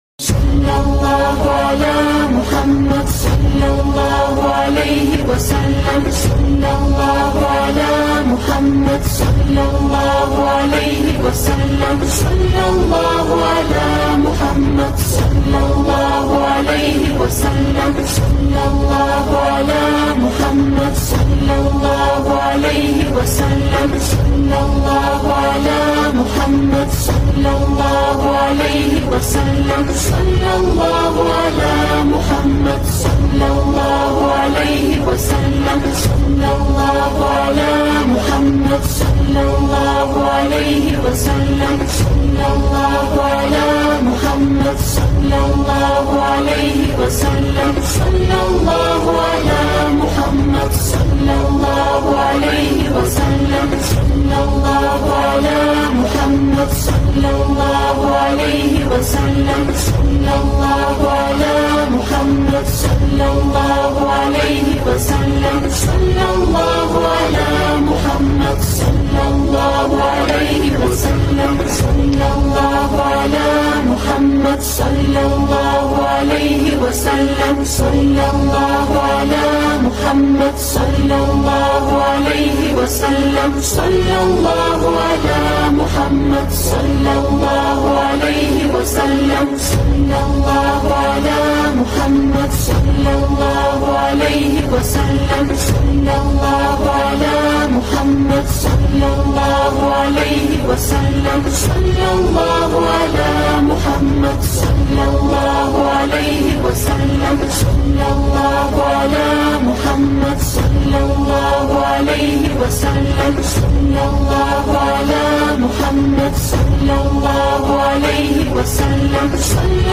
Arabic Nasheed